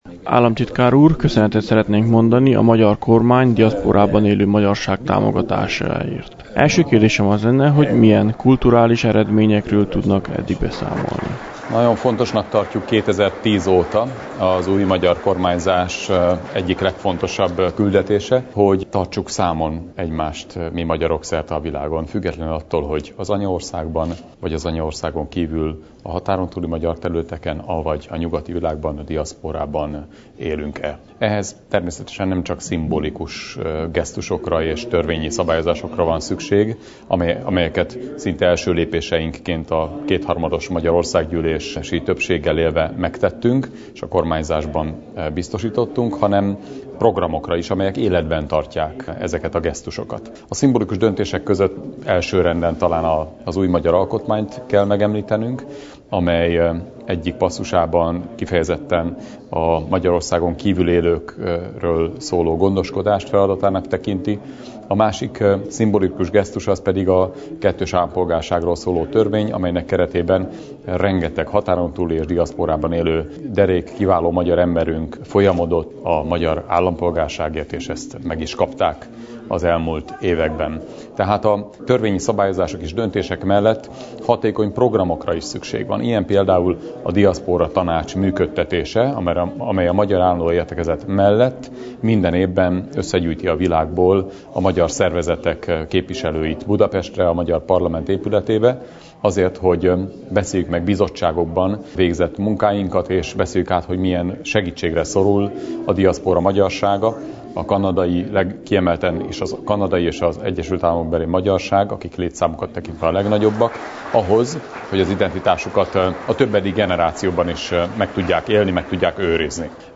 Ezek után készültek úgy videó interjúk, mint rádió interjúk is.